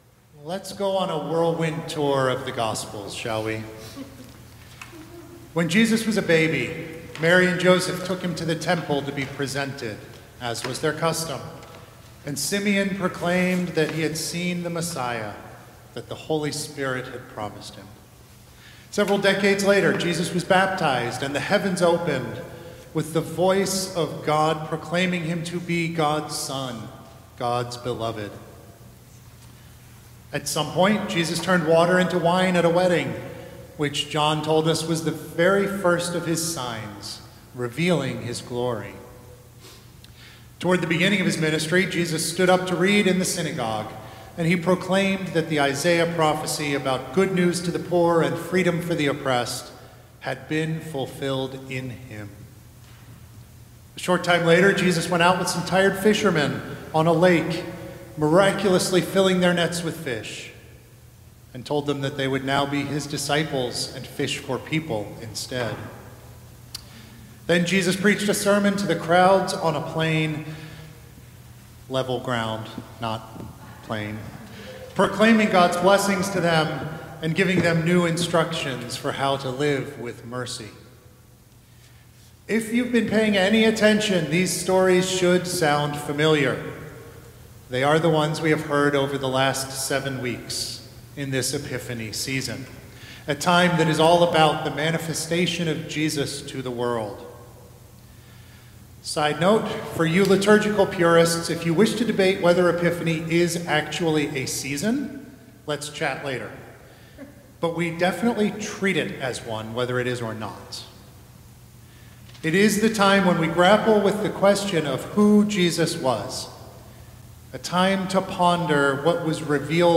Sermons | Emmanuel Episcopal Church